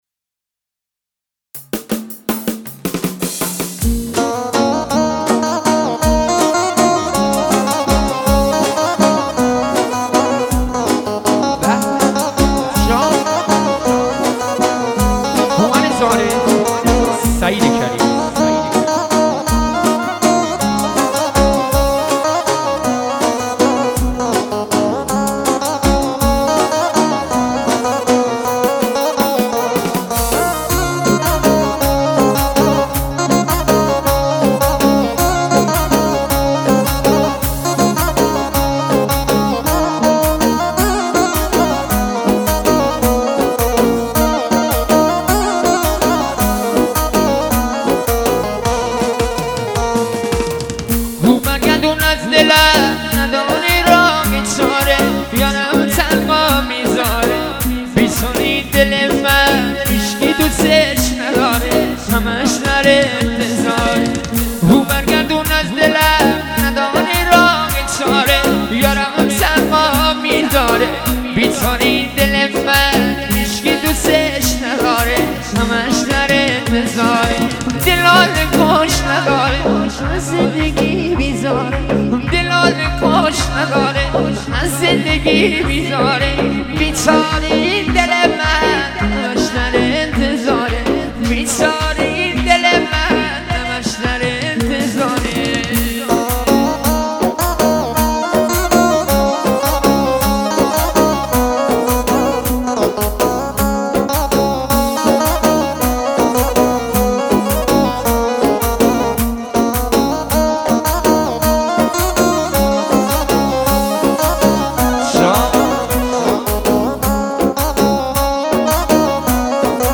ارکستی شاد